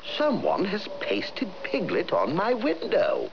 The voices of Pooh and his friends